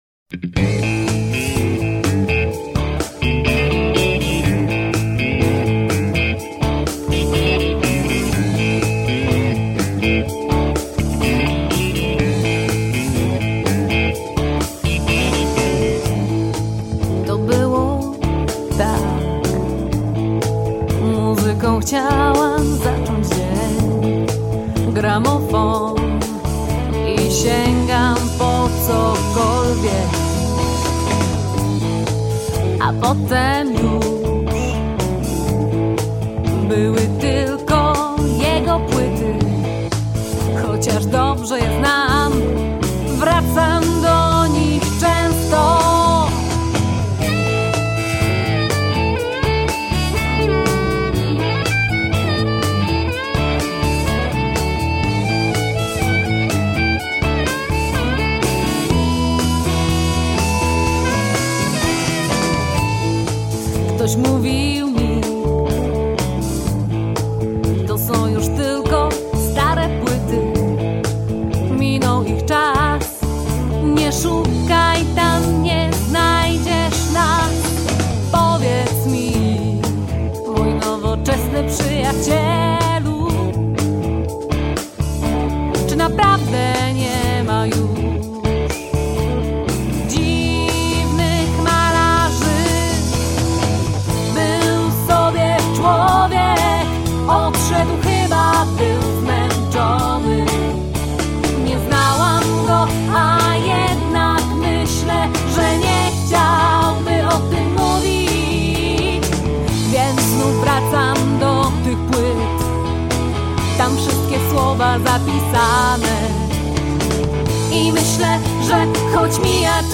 rock blues